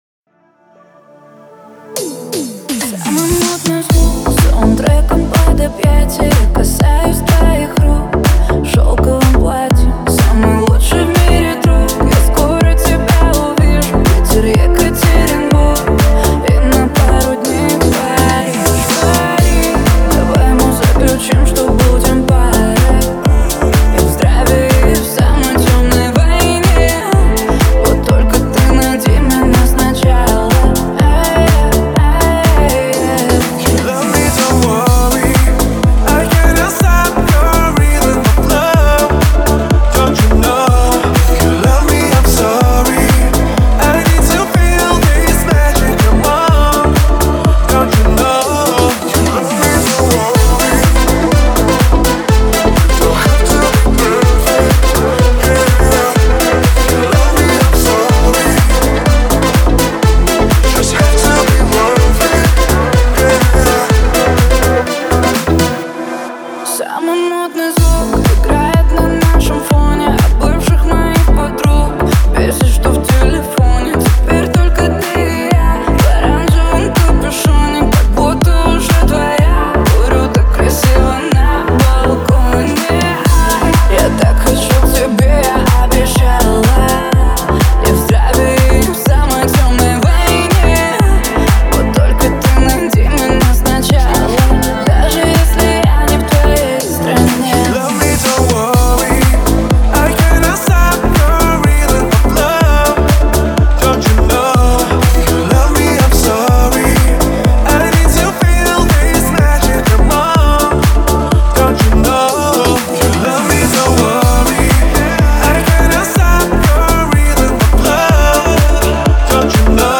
это современная поп-песня в стиле EDM